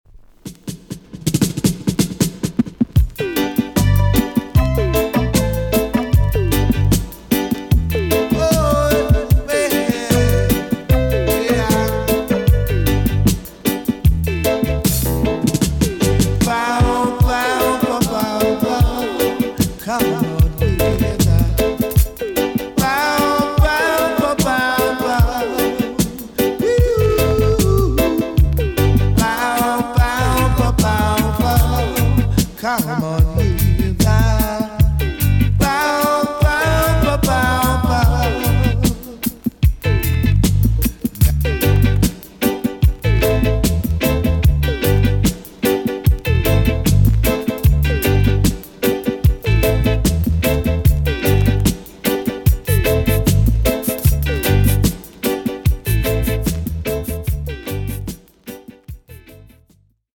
TOP >DISCO45 >VINTAGE , OLDIES , REGGAE
EX- 音はキレイです。